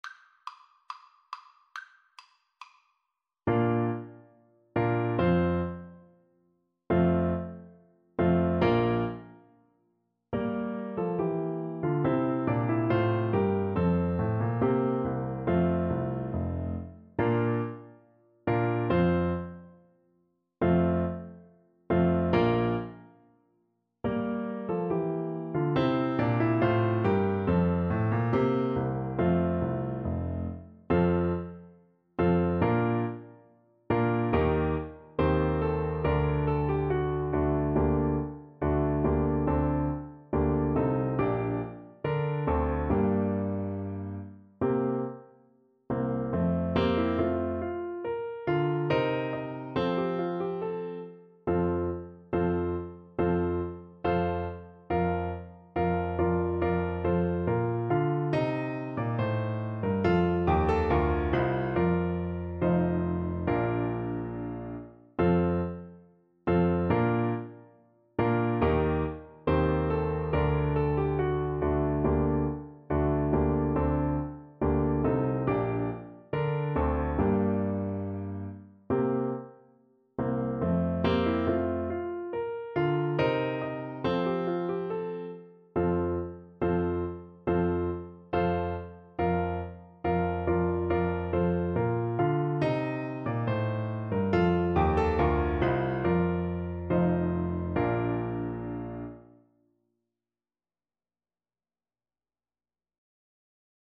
Classical (View more Classical Soprano Saxophone Music)